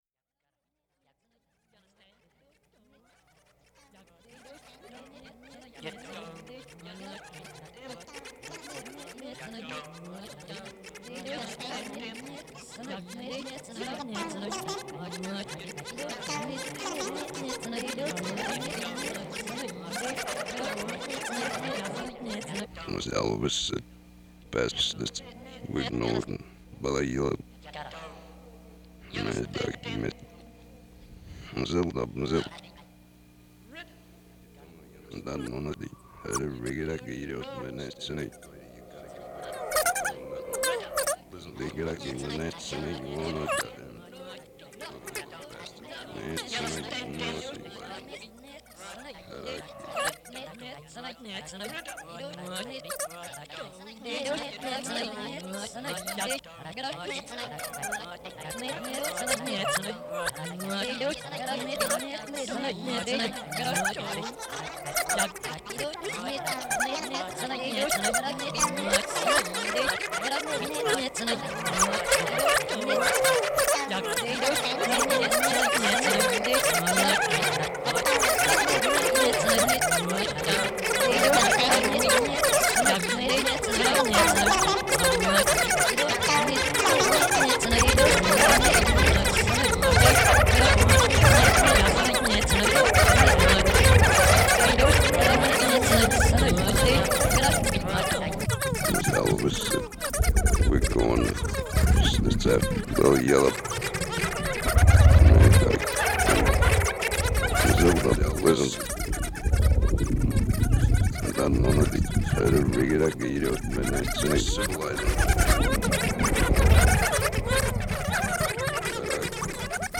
This is my earliest acknowledged electroacoustic work. It was made in the studios of Toronto University during the Canadian winter of 1972/73.
It uses an obsolete,  tape loop playing keyboard, and a couple of old, noisy Ampex AG440 tape machines.
I attempted to emulate this by the use of tape reversal, which is gradually removed as the protagonist (a rather sceptical, but resonant voiced  studio technician, co-opted for the task) proclaims the central thread of the text.